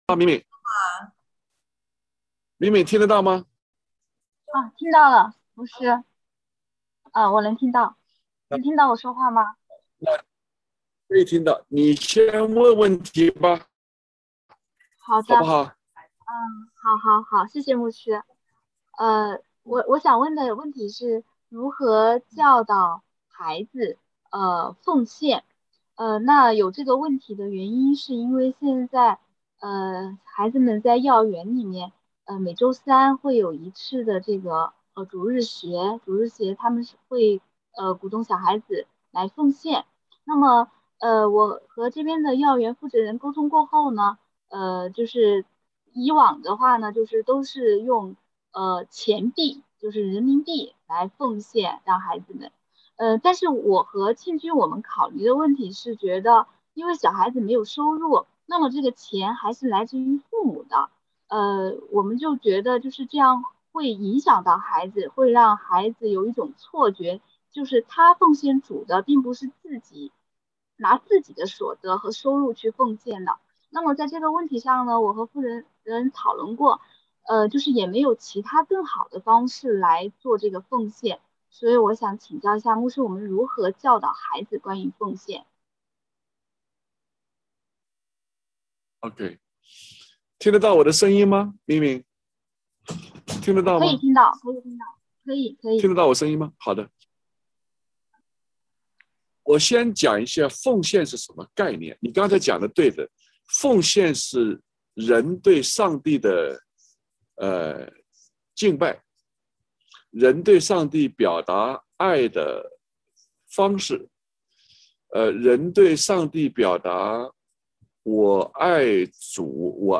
主日讲道